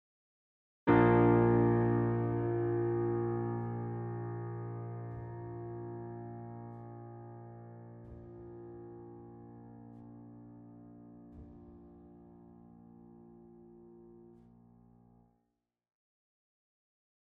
Garritan CFX pedal.mp3 Garritan CFX pedal.mp3 678 KB · Просмотры: 1.548 Screen Shot 2018-04-06 at 03.01.46.JPEG 130,2 KB · Просмотры: 166 Screen Shot 2018-04-06 at 03.01.37.JPEG 99,5 KB · Просмотры: 161